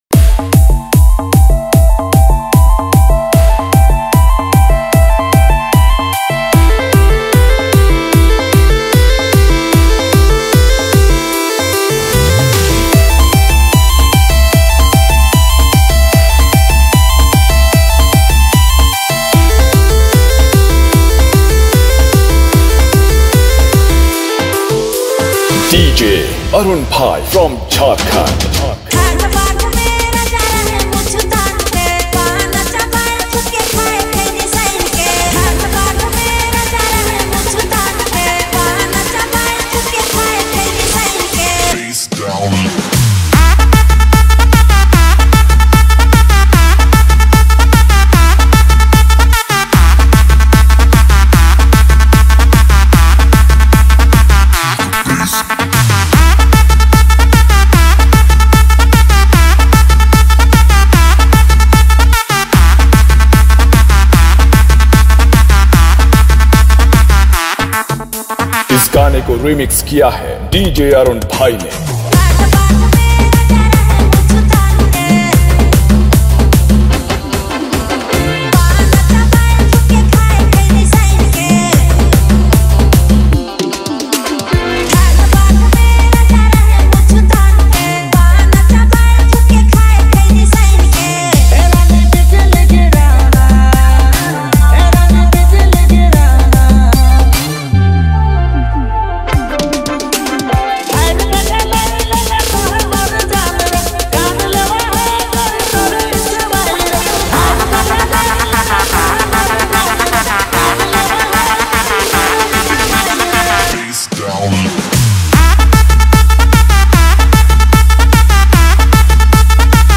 Category : Trending Remix Song